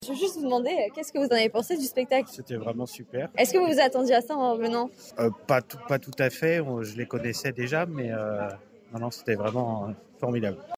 Photo et interviews réalisées par nos étudiants de BTS DATR (Développement et Animation des Territoires Ruraux).
interview-spectateurs-03.mp3